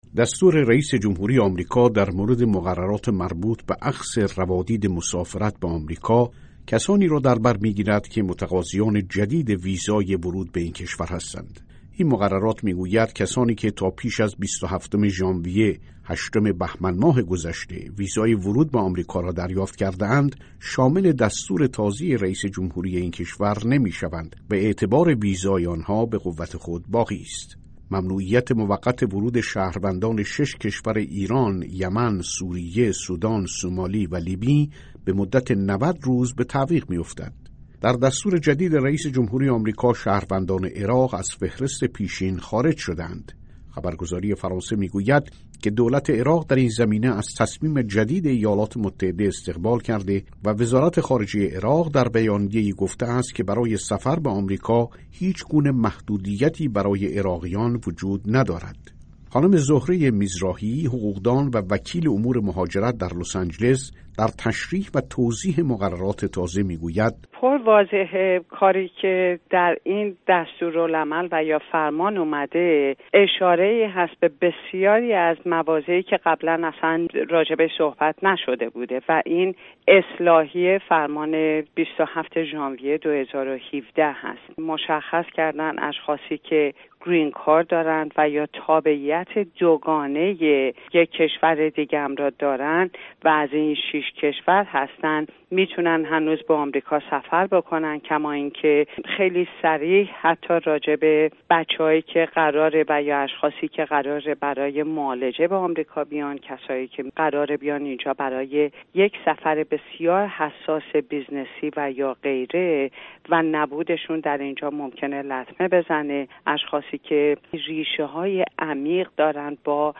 گفت‌وگوی
با یک وکیل مهاجرتی درباره فرمان اجرایی ممنوعیت ورود اتباع شش کشور به آمریکا